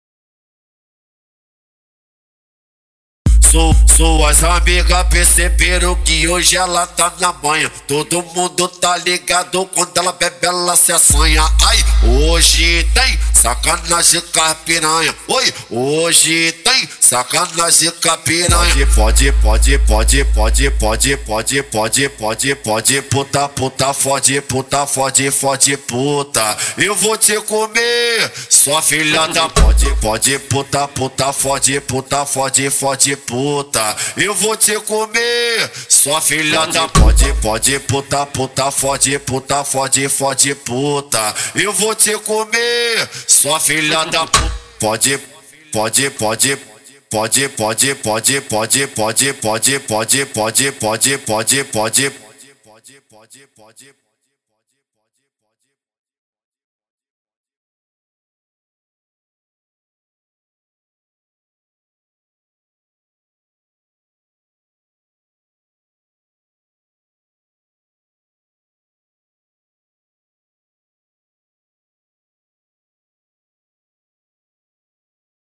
Acapella de Funk